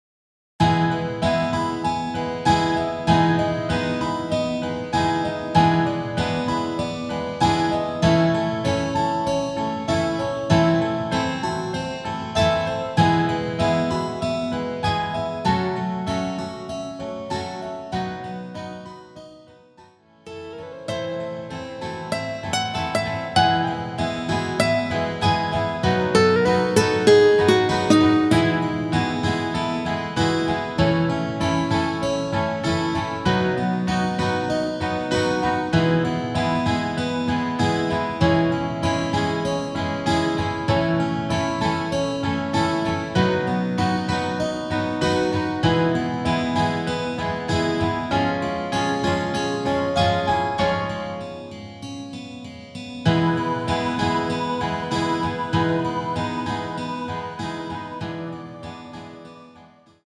남성, 여성 모두 부르실수 있는 키 입니다.(여성분은 옥타브 올려서 부르시면 됩니다.)
Eb
앞부분30초, 뒷부분30초씩 편집해서 올려 드리고 있습니다.
중간에 음이 끈어지고 다시 나오는 이유는
곡명 옆 (-1)은 반음 내림, (+1)은 반음 올림 입니다.